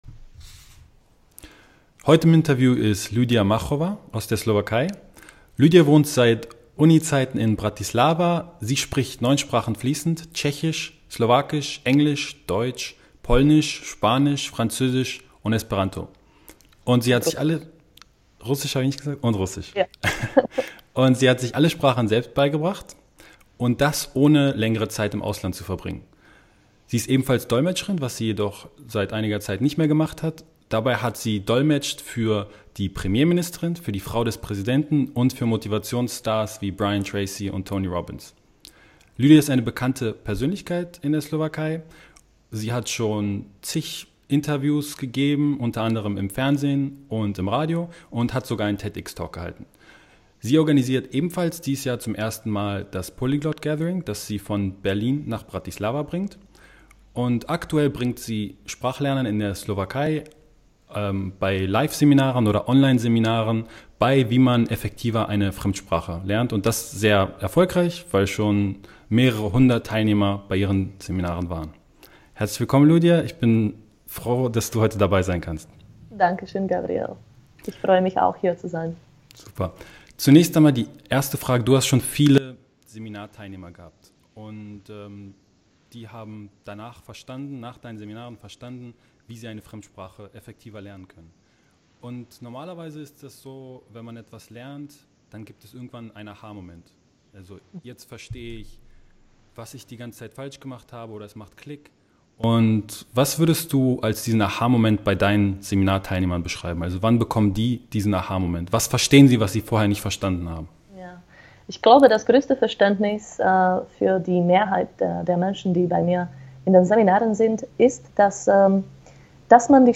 Wie wirst Du effektiver Sprachlerner? Interview